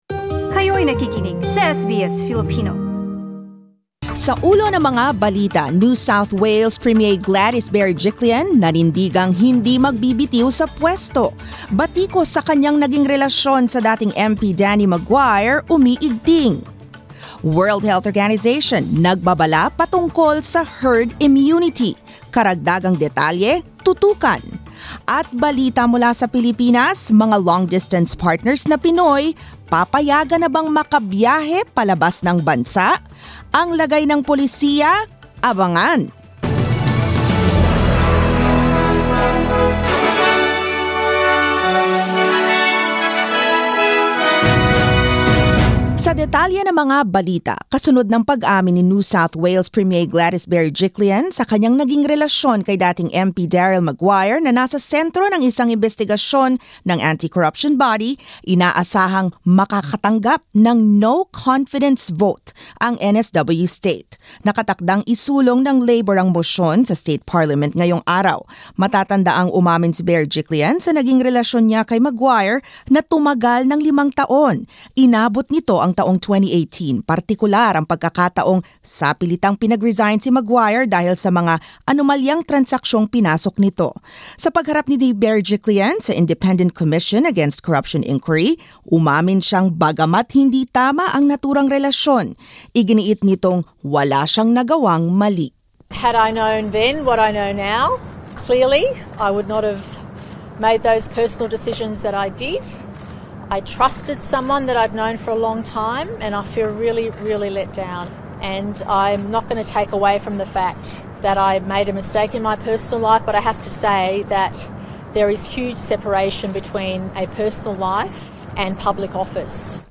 SBS News in Filipino, Tuesday 13 October